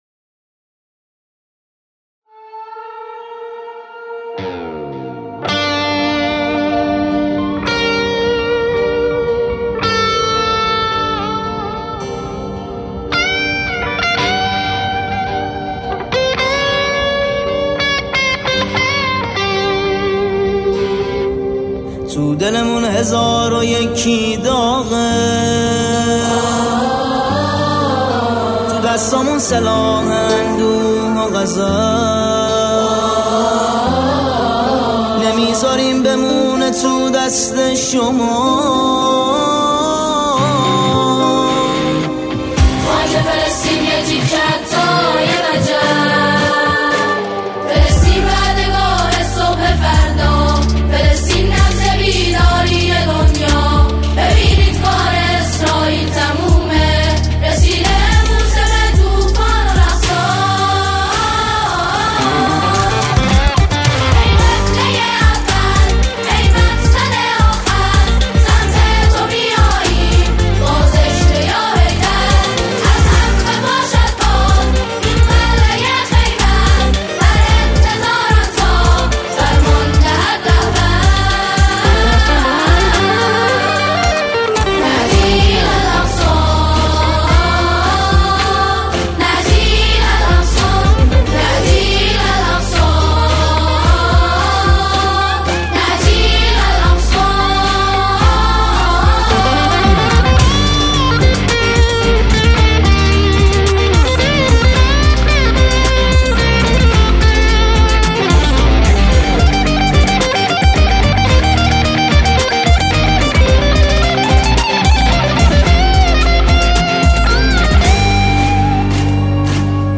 سرودهای فلسطین